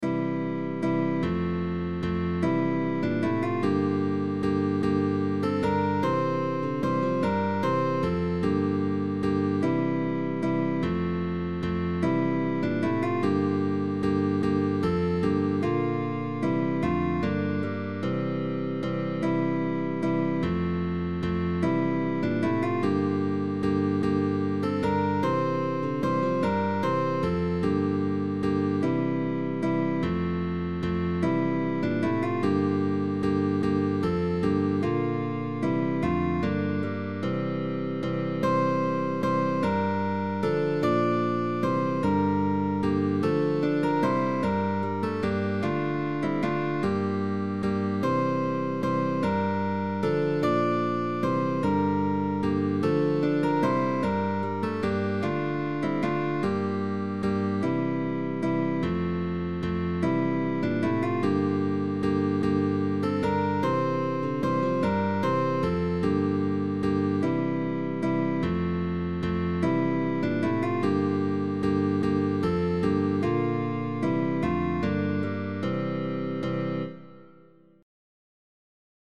Guitar Quarters
Early music